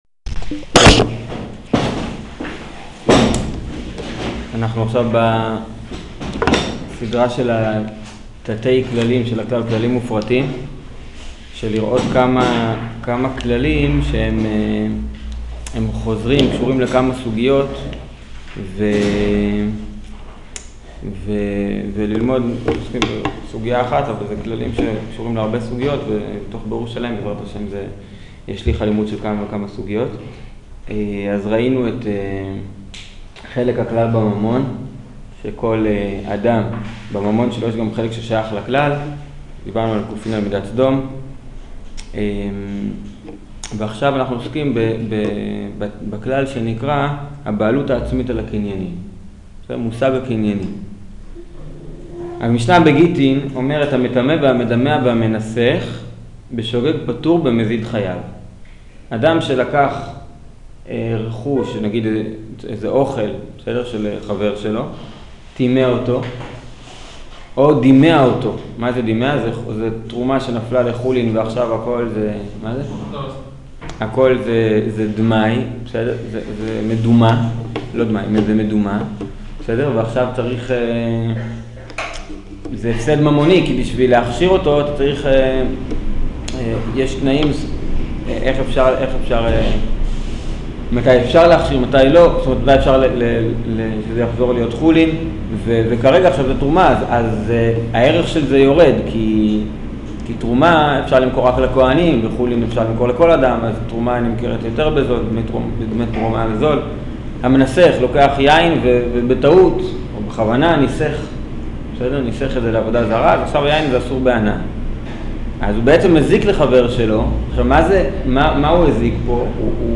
שיעור הבעלות העצמית על הקניינים